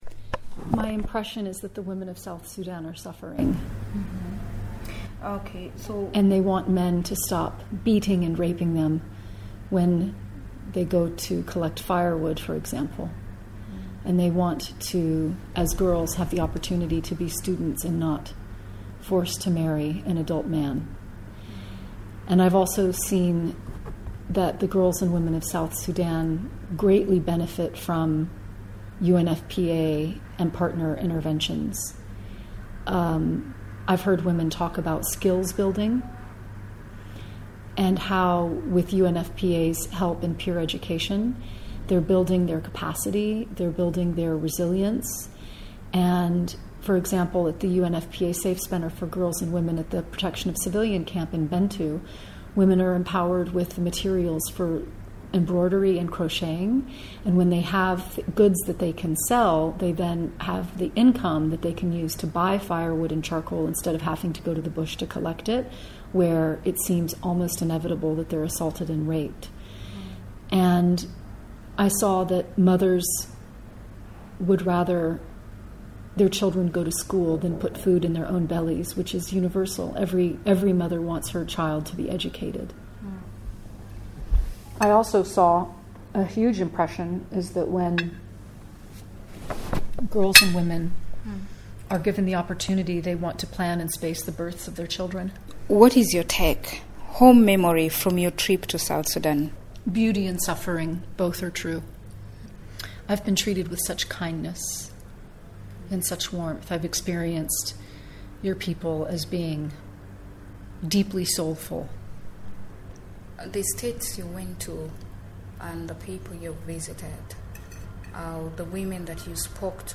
She begins by explaining her impression of the trip to South Sudan.